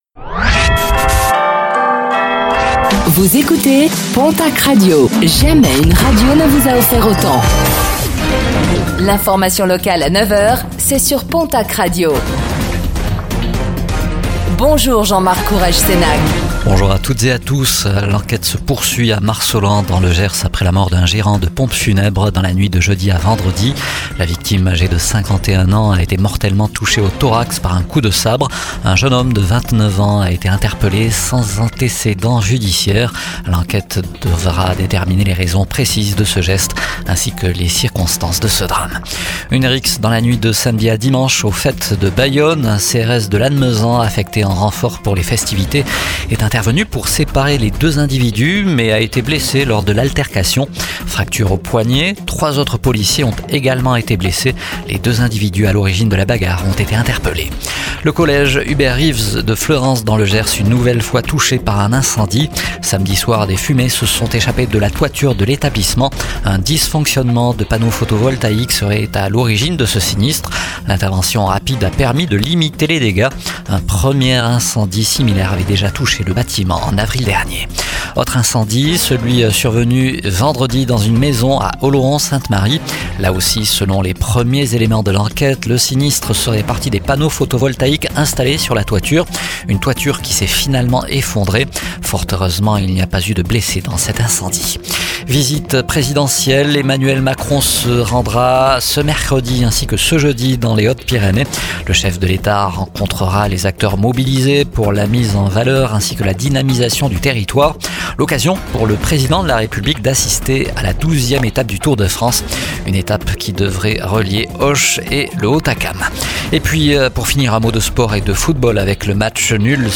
Réécoutez le flash d'information locale de ce lundi 14 juillet 2025